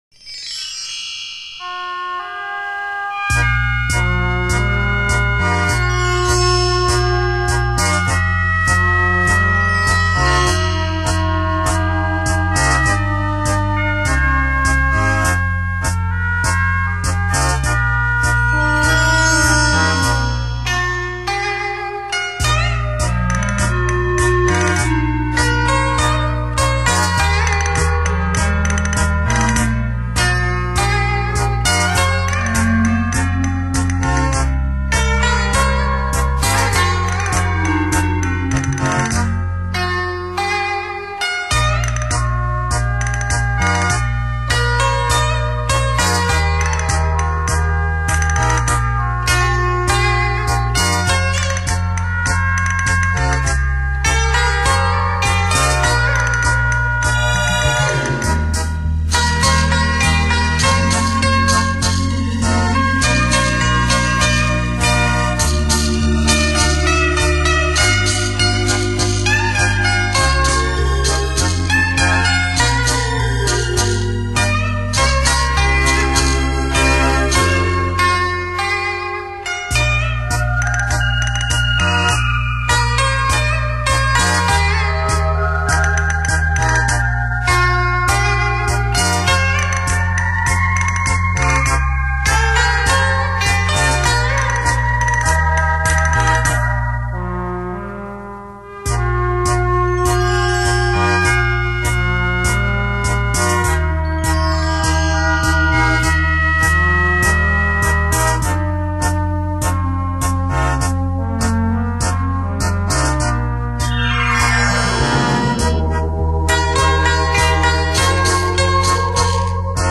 这个专辑音乐的立体感很不错!